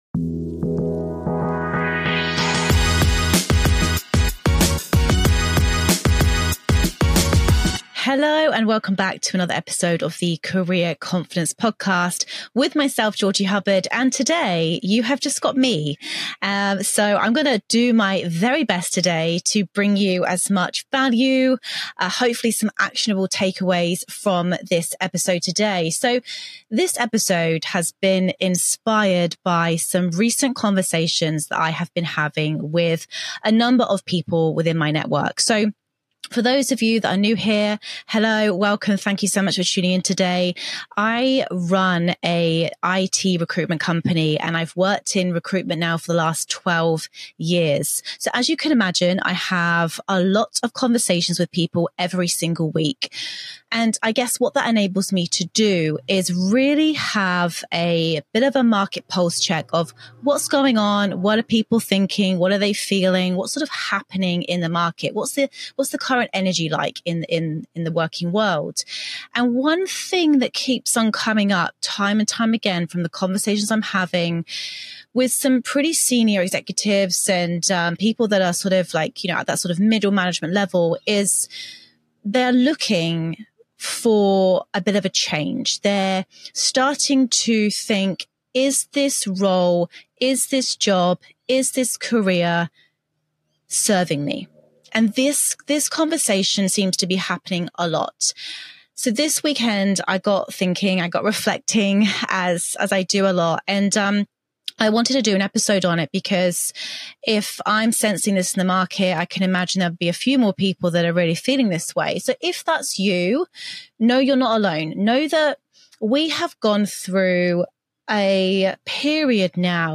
In this week's solo episode